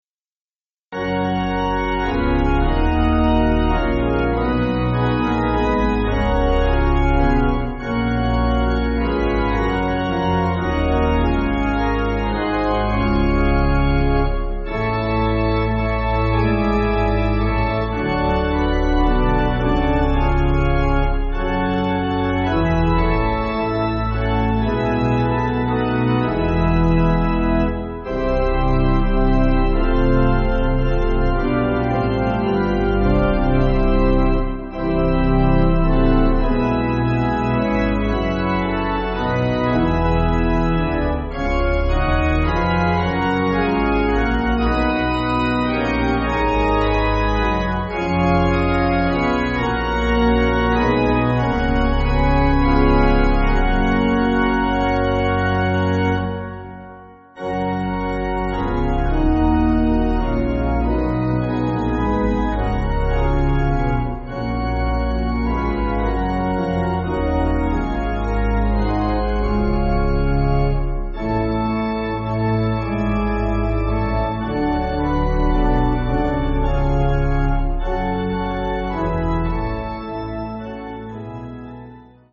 8.7.8.7.D
Organ